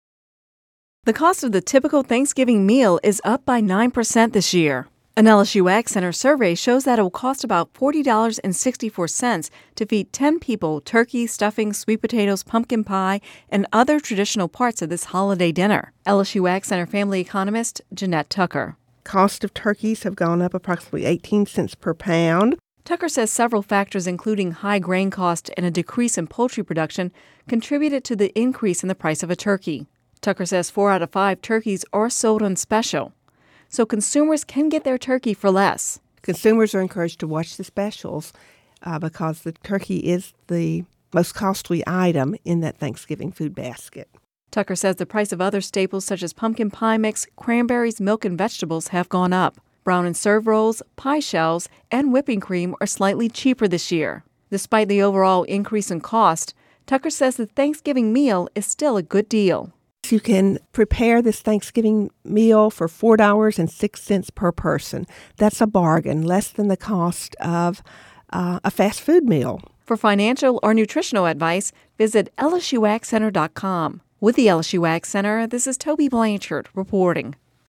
(Radio News 11/22/10) The cost of the typical Thanksgiving meal is up by 9 percent this year. An LSU AgCenter survey shows it will cost about $40.64 to feed 10 people turkey, stuffing, sweet potatoes, pumpkin pie and other traditional parts of this holiday dinner.